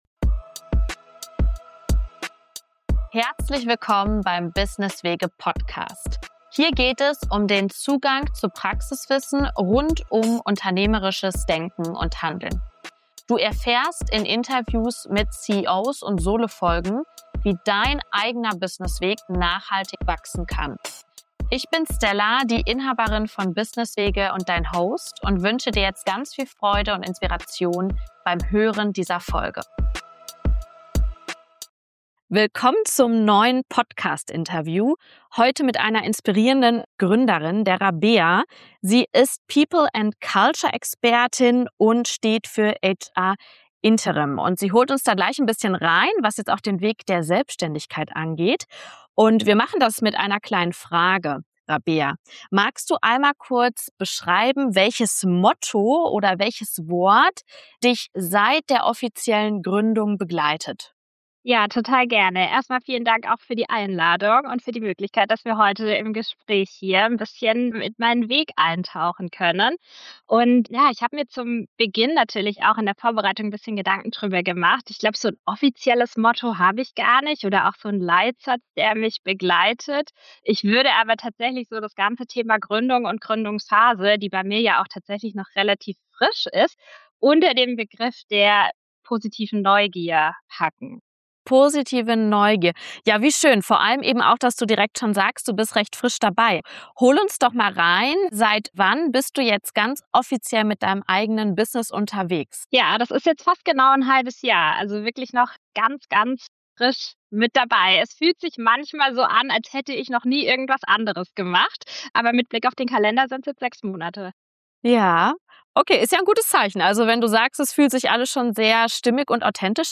In unserem Gespräch geht es auch um die Frage, wie Unternehmen in Wachstumsphasen die richtigen HR-Strategien entwickeln und welchen Wert der externe Blick dabei hat. Wir sprechen darüber, warum Flexibilität und Struktur kein Widerspruch sind, wie ein unterstützendes Umfeld den Gründungserfolg beeinflusst und was Erfolg jenseits monetärer Ziele bedeuten kann.